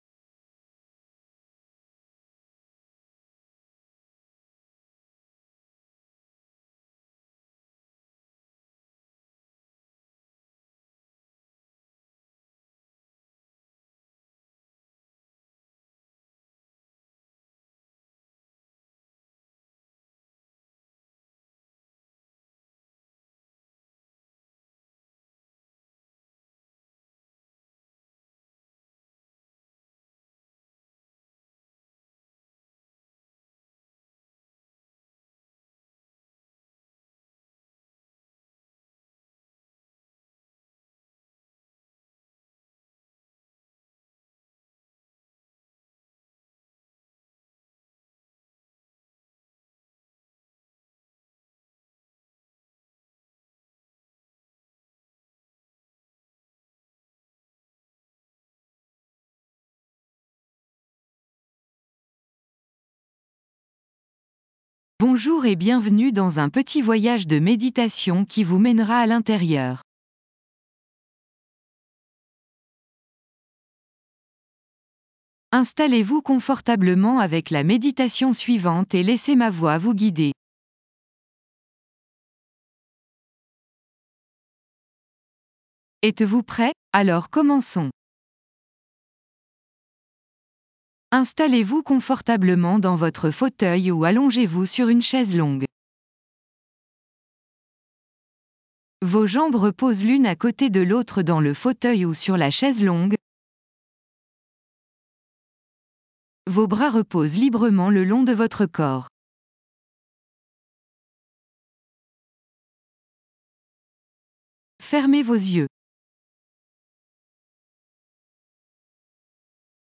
Restez concentré sur ces passages pendant que vous laissez la musique vous submerger.